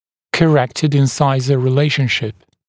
[kə’rektəd ɪn’saɪzə rɪ’leɪʃnʃɪp][кэ’рэктэд ин’сайзэ ри’лэйшншип]скорректированное соотношение резцов, исправленное соотношение резцов